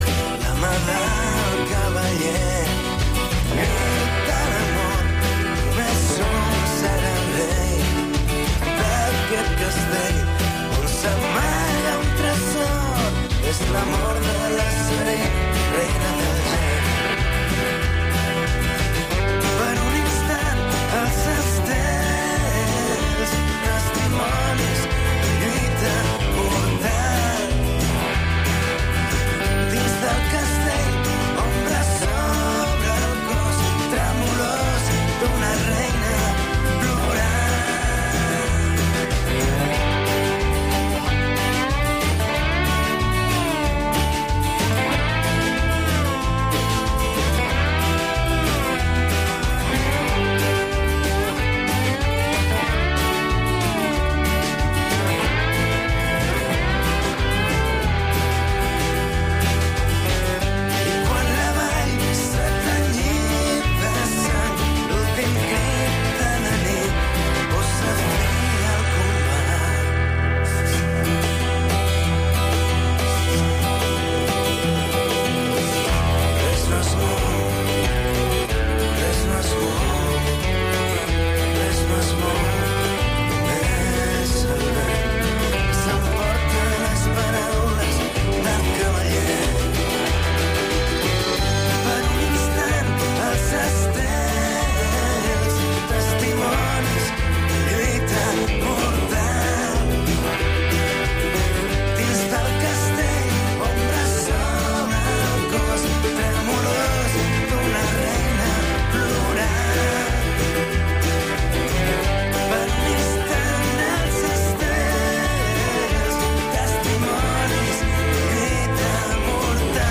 Programa de música clàssica